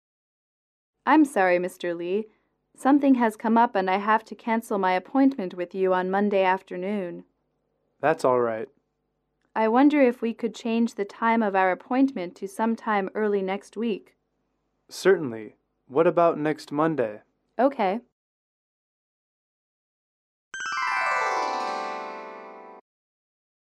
英语主题情景短对话53-2：推迟约会(MP3)